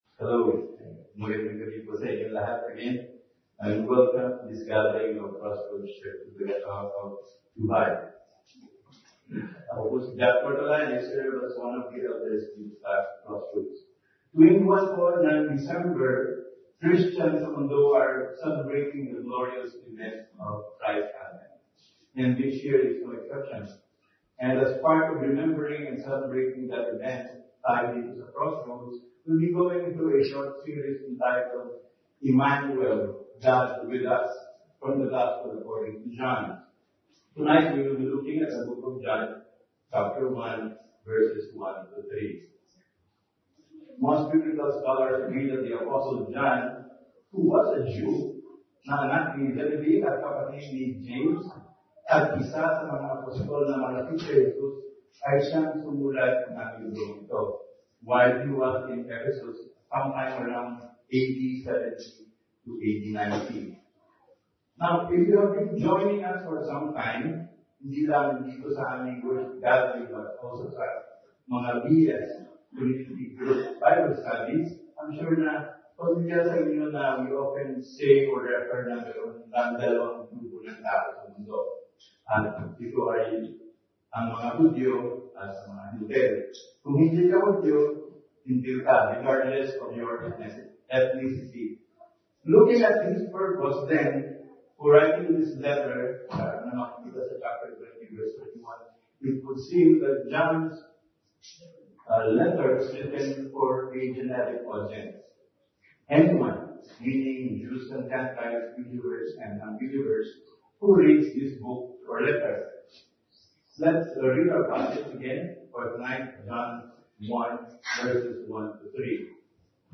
2december2021-sermon.mp3